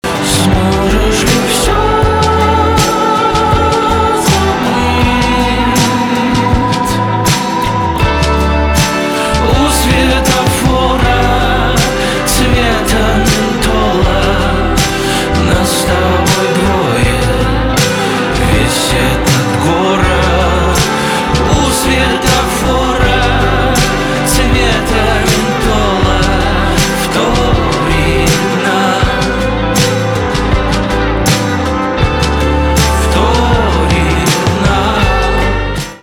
инди
гитара , барабаны
спокойные
чувственные